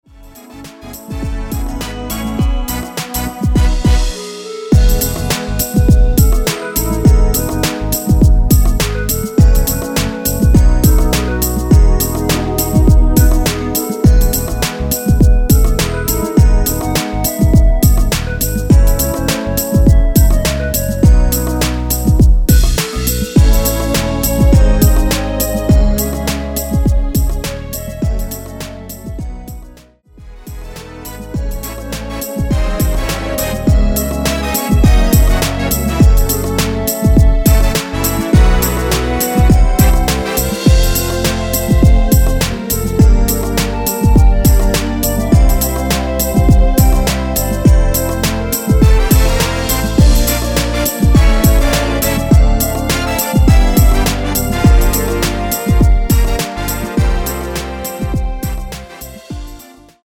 원키에서(-6)내린 멜로디 포함된 MR입니다.(미리듣기 확인)
앞부분30초, 뒷부분30초씩 편집해서 올려 드리고 있습니다.
(멜로디 MR)은 가이드 멜로디가 포함된 MR 입니다.